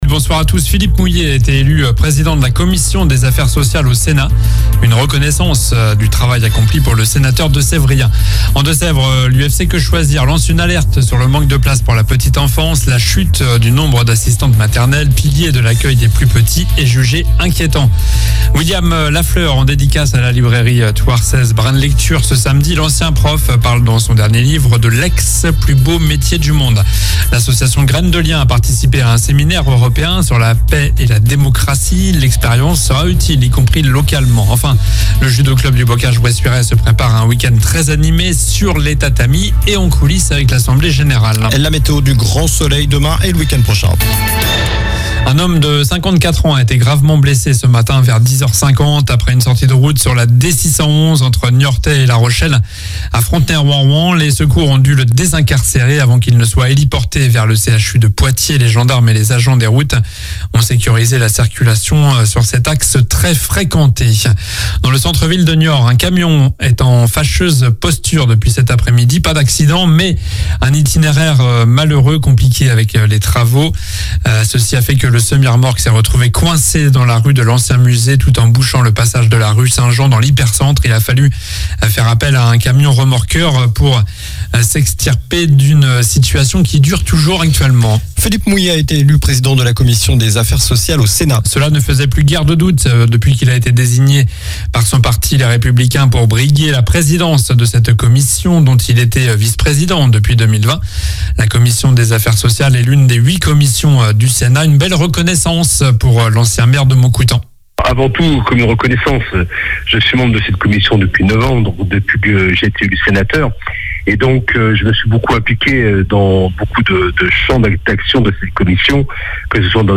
Journal du jeudi 05 octobre (soir)